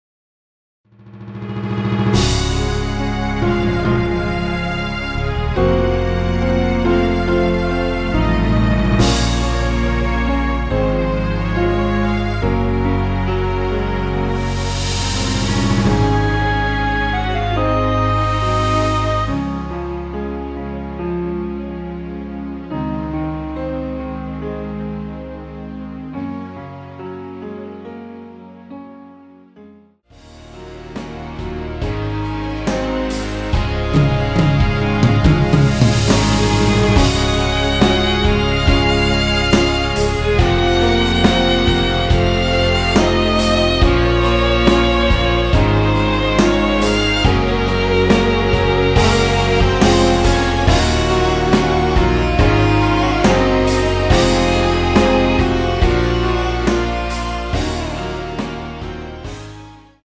Db
◈ 곡명 옆 (-1)은 반음 내림, (+1)은 반음 올림 입니다.
앞부분30초, 뒷부분30초씩 편집해서 올려 드리고 있습니다.